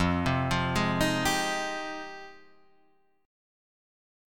Fm9 chord {1 3 3 1 4 3} chord